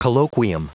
Prononciation du mot colloquium en anglais (fichier audio)
colloquium.wav